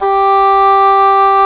WND OBOE G4.wav